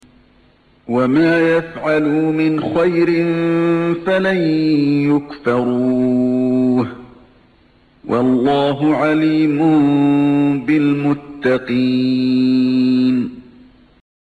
1- رواية حفص
استمع للشيخ علي الحذيفى من هنا